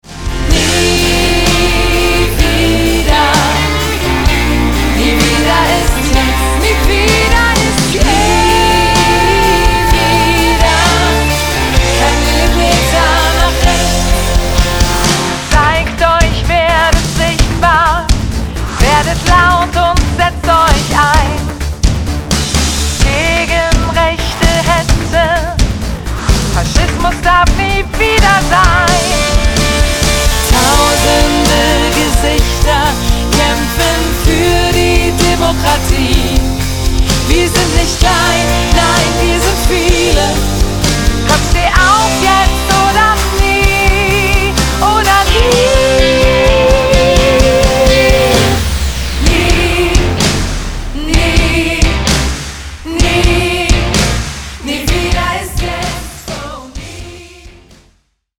im Studio aufgenommen
Drums
Gitarre und E-Bass)  Backing Vocals